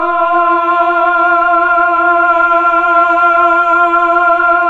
Index of /90_sSampleCDs/Keyboards of The 60's and 70's - CD1/VOX_Melotron Vox/VOX_Tron Choir